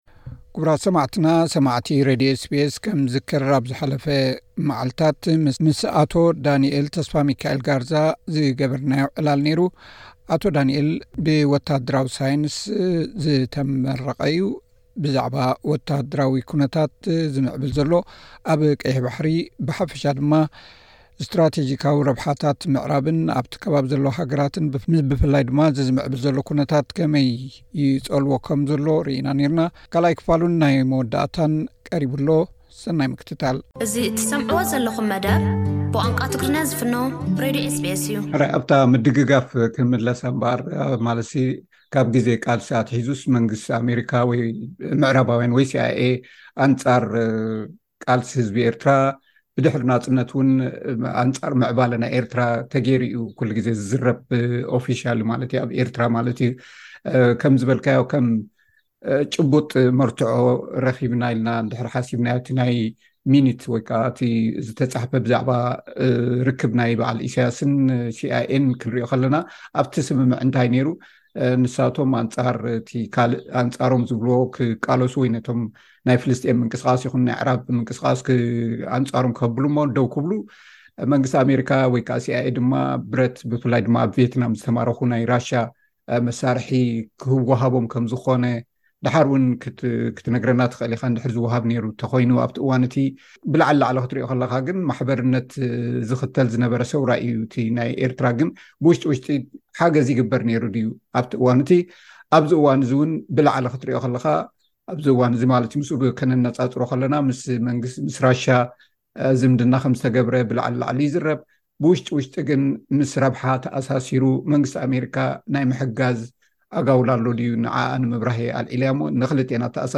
ካልኣይ ክፋል ቃለ መሕትት